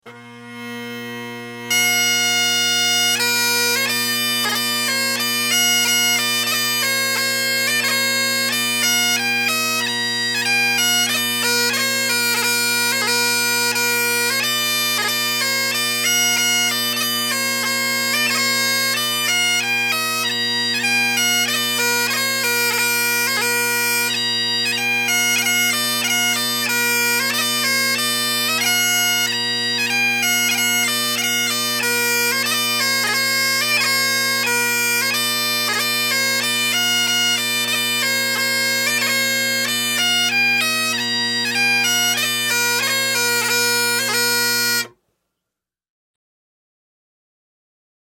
Bagpipe Music Samples
Tunes for Weddings